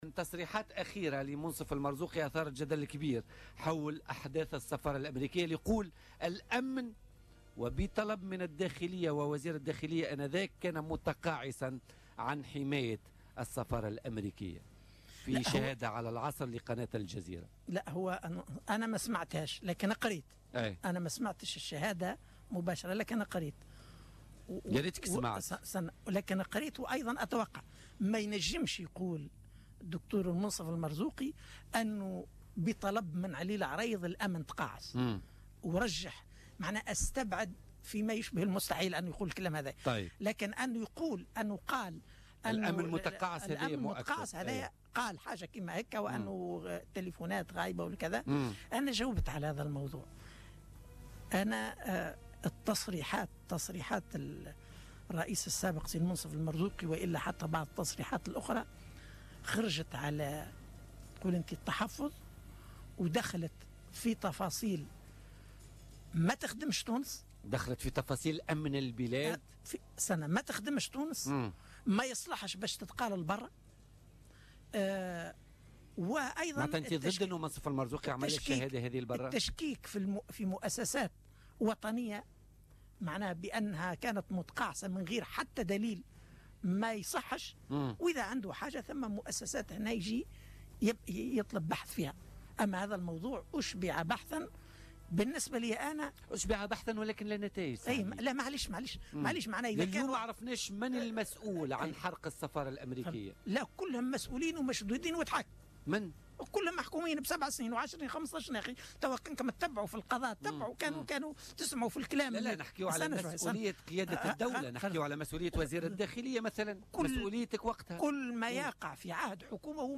قال القيادي في حركة النهضة علي العريض ضيف بولتيكا اليوم الخميس 24 أوت 2017 إن تصريحات الرئيس الأسبق المنصف المرزوقي بخصوص تقاعس الأمن في أحداث السفارة الأمريكية خرجت عن واجب التحفظ المفروض عليه ولاتصلح أن تقال في الخارج.